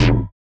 Index of /RoBKTA Sample Pack Supreme/BASSES
04_Groan.wav